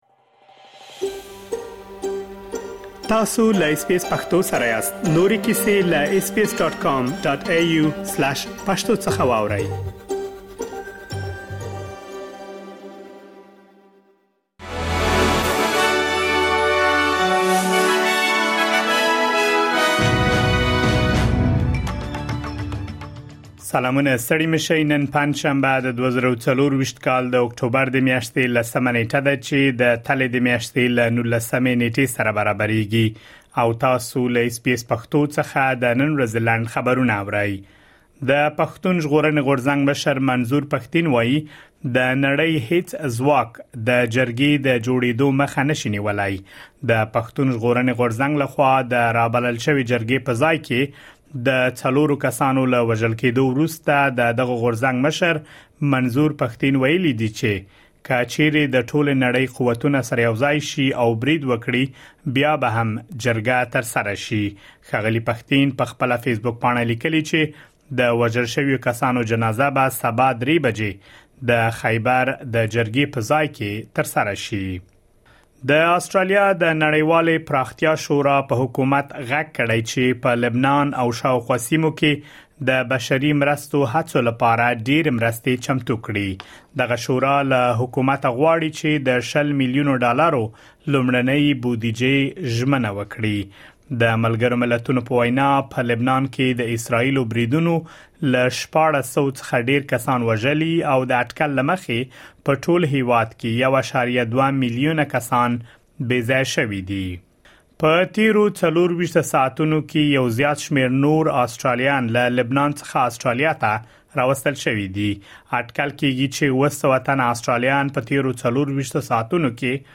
د اس بي اس پښتو د نن ورځې لنډ خبرونه|۱۰ اکټوبر ۲۰۲۴
د اس بي اس پښتو د نن ورځې لنډ خبرونه دلته واورئ